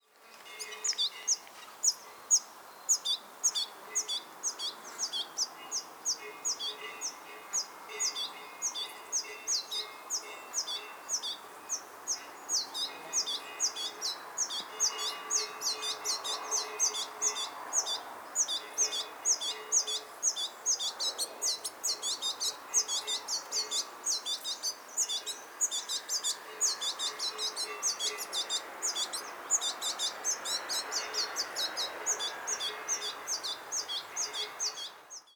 Venturone alpino
• (Carduelis citrinella)
Venturone-alpino.mp3